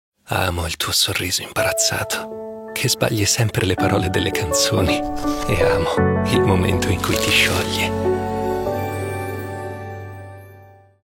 spot TV e web
Caratteristiche voce